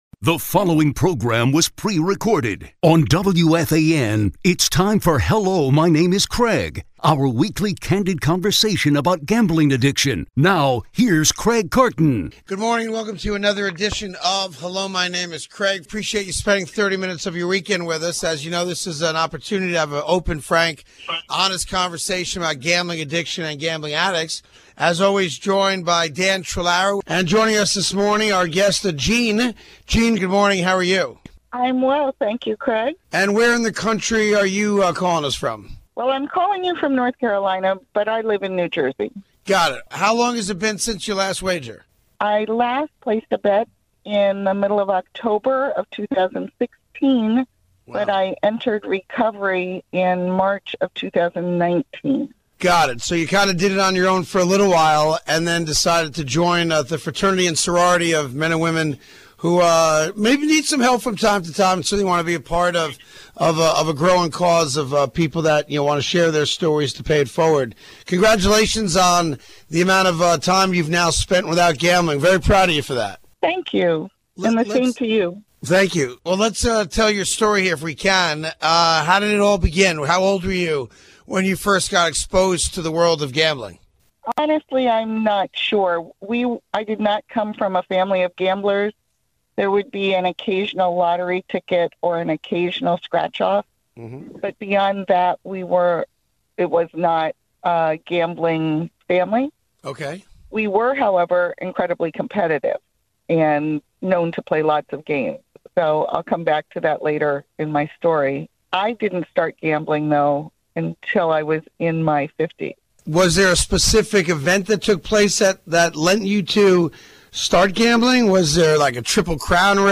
A WEEKLY CANDID CONVERSATION ON GAMBLING ADDICTION